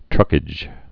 (trŭkĭj)